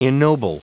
Prononciation du mot ennoble en anglais (fichier audio)
Prononciation du mot : ennoble